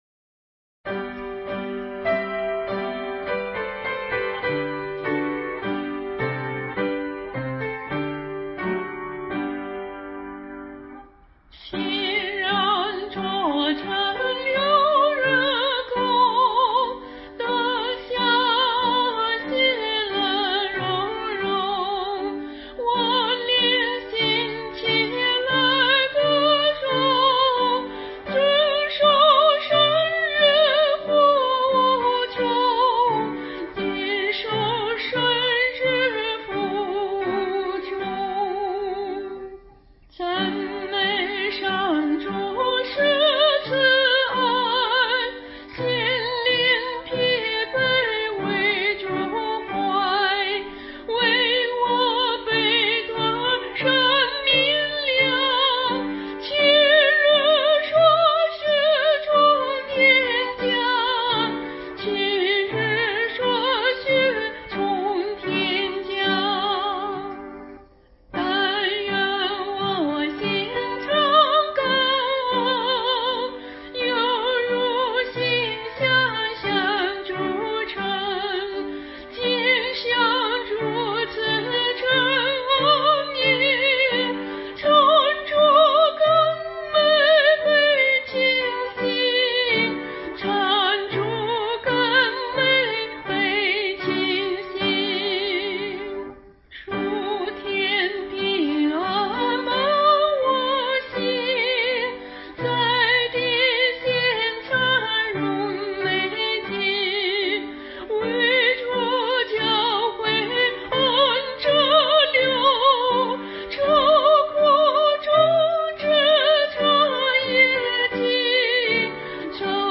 原唱